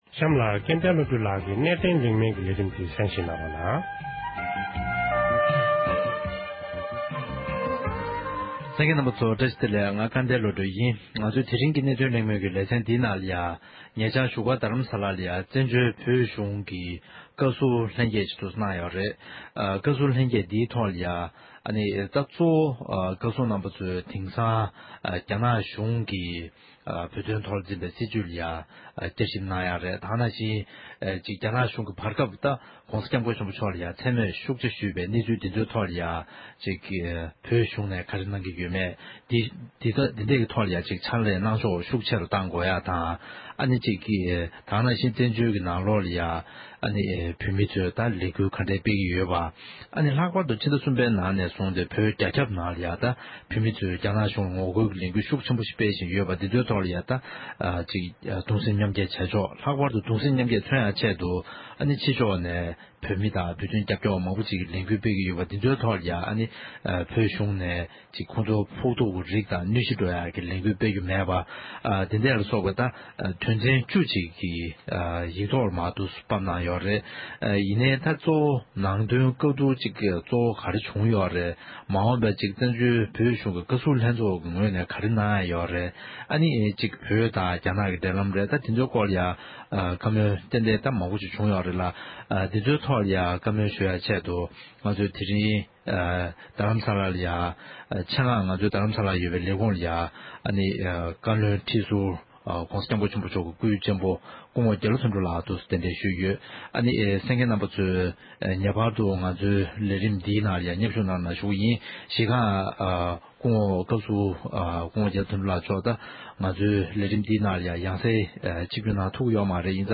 བཙན་བྱོལ་བོད་གཞུང་གི་བཀའ་ཟུར་རྣམ་པས་བཞུགས་སྒར་དུ་ཉིན་ལྔའི་རིང་བོད་ཀྱི་གནད་དོན་ཐོག་ལྷན་ཚོགས་ཤིག་གནང་བའི་ཐོག་བགྲོ་གླེང་ཞུས།